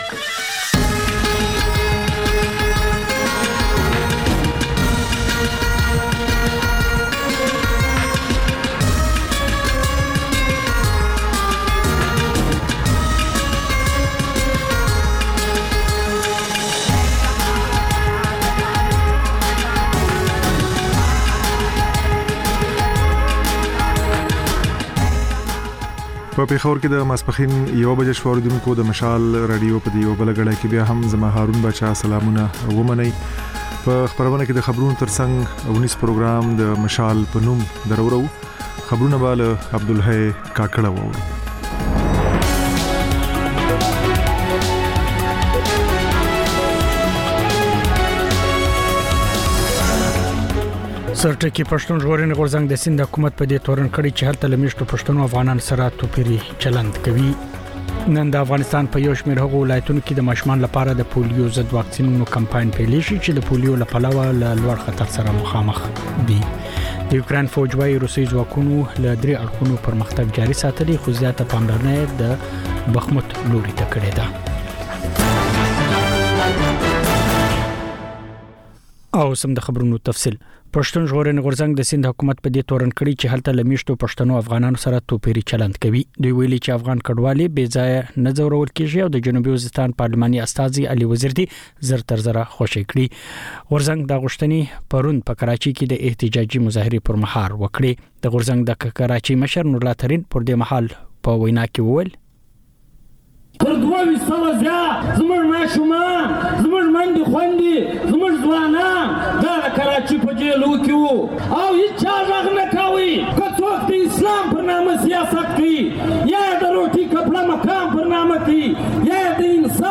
د مشال راډیو لومړۍ ماسپښينۍ خپرونه. په دې خپرونه کې تر خبرونو وروسته بېلا بېل رپورټونه، شننې، مرکې خپرېږي. په دې ګړۍ کې اوونیزه خپرونه هم خپرېږي.